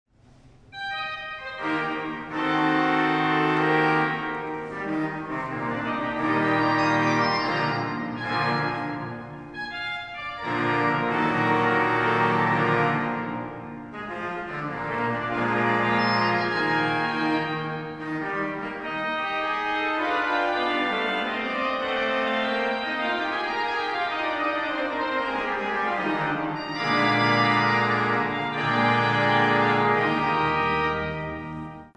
Concert sur l'orgue Callinet de l'église Notre-Dame du Marthuret à Riom
Les extraits montrent quelques échantillons des sonorités particulières de l'orgue.